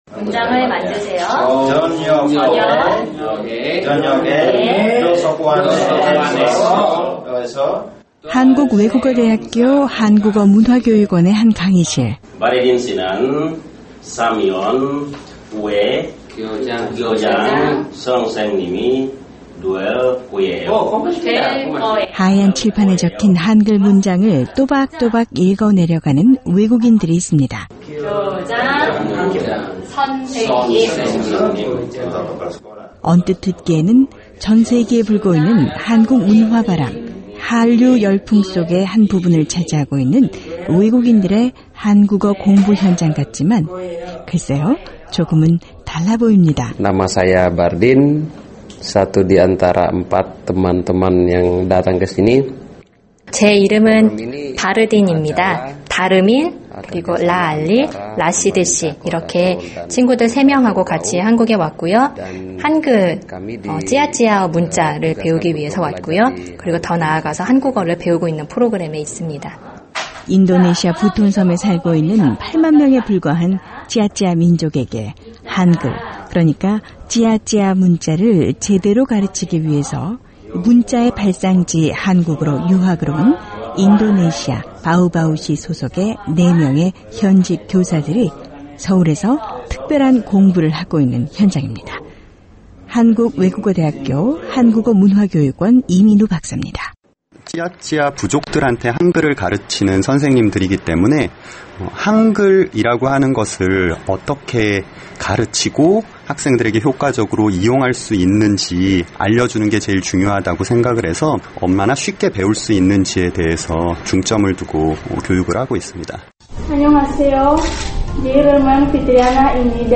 멀리 인도네시아 부톤섬에서 서울을 찾아온 선생님들이라는데요, 3년 전 찌아찌아 부족에게 도입된 새로운 문자인 한글의 발상지인 한국에서 특별한 연수를 받고 있습니다. 오늘은 인도네시아 사람들의 한글 읽는 소리가 유쾌한 한국외국어대학교로 가보겠습니다.